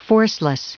Prononciation du mot forceless en anglais (fichier audio)
Prononciation du mot : forceless